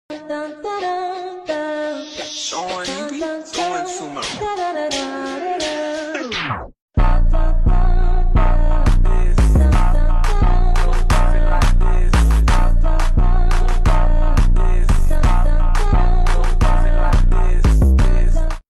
(Slowed€reverb)